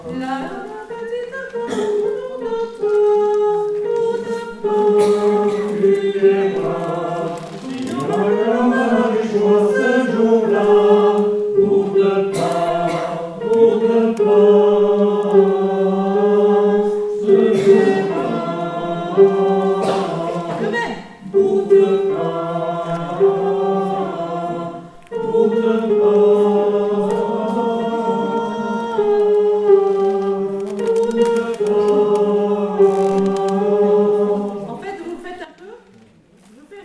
Répétitions avant Bonnegarde : Revenir à l'accueil
Fin messieurs
Fin Hommes.mp3